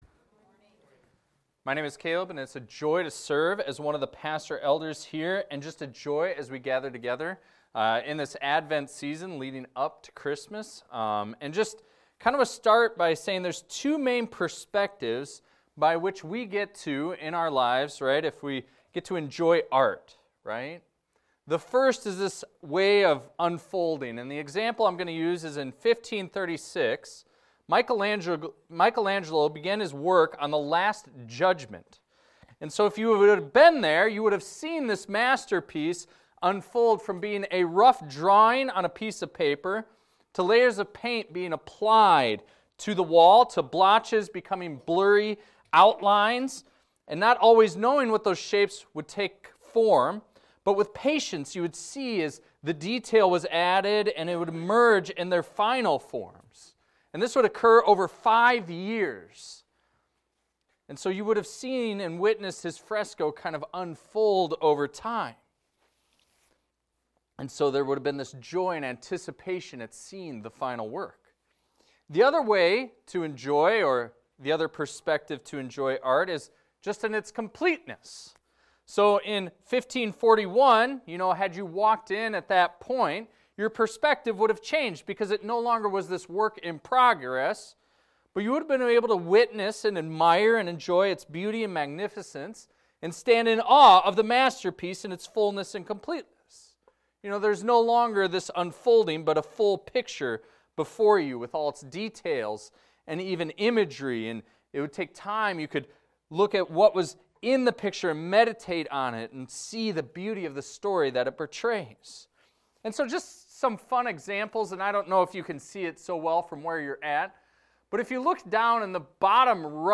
This is a recording of a sermon titled, "From Genesis to Jesus."